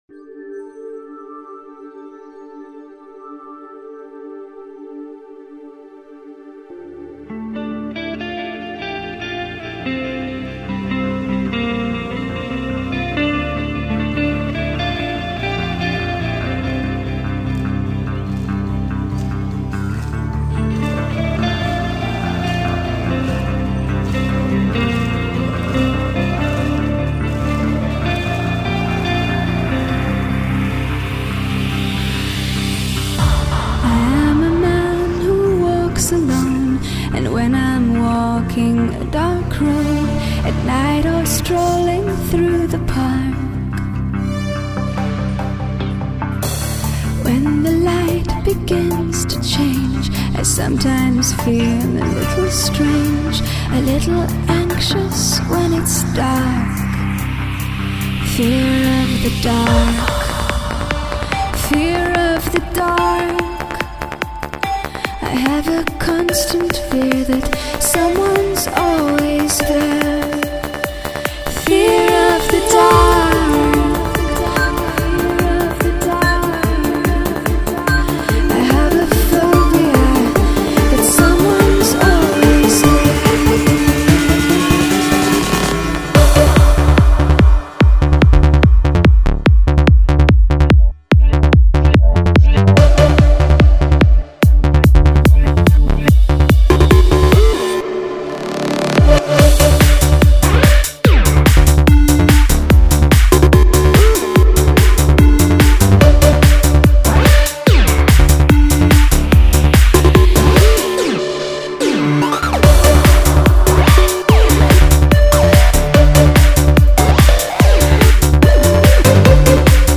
sacudir el cuerpo al compás de los sonidos electrónicos